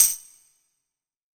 6TAMBOURIN18.wav